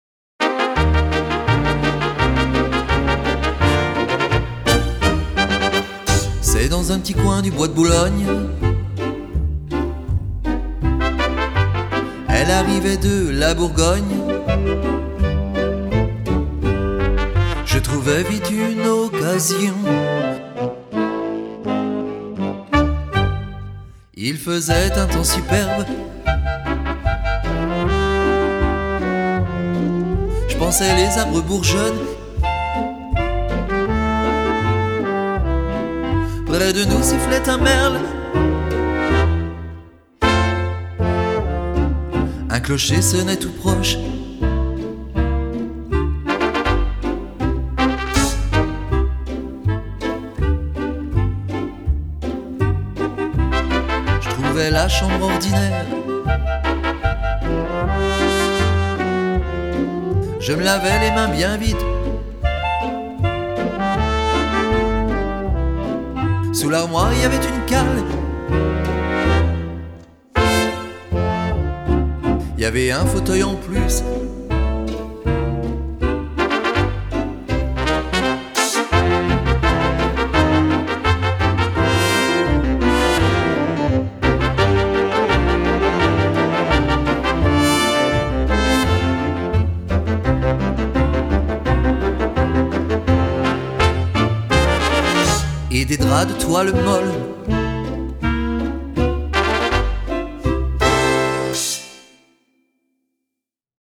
La version chantée avec les trous (Expert)